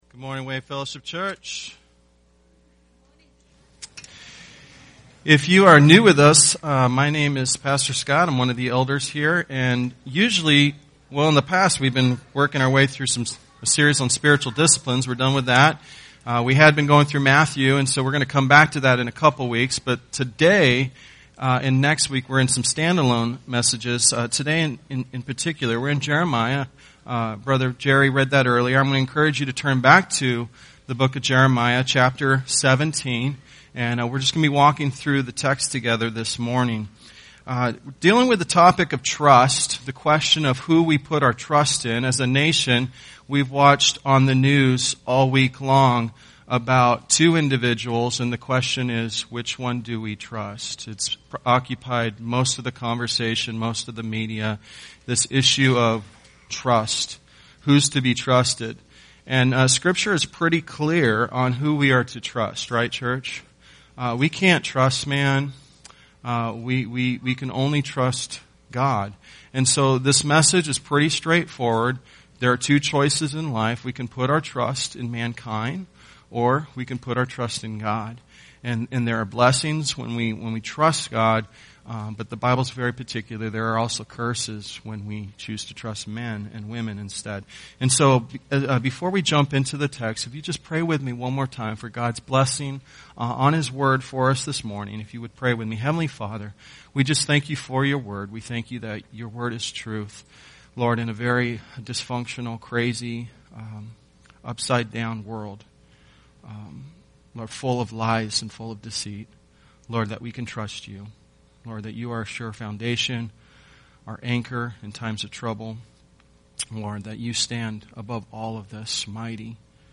Tagged with Sunday Sermons